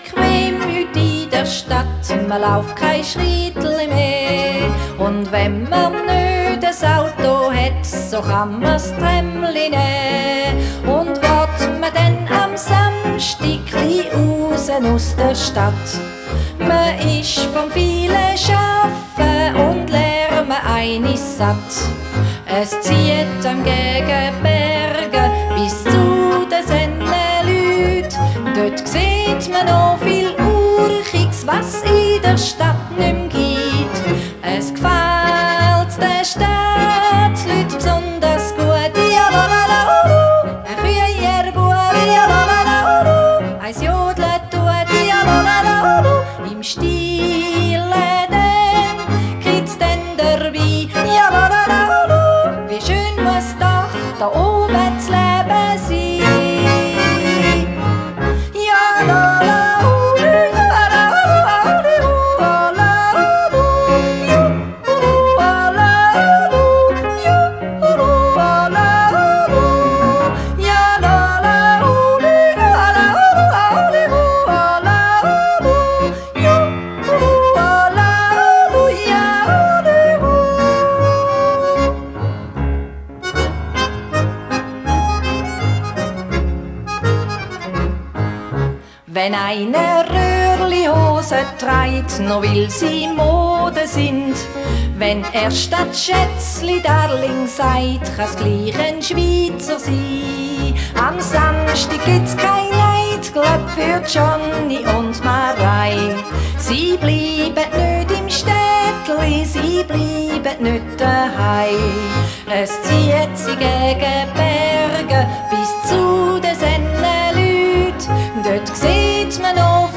Genre: Folk / Country / Retro / Yodel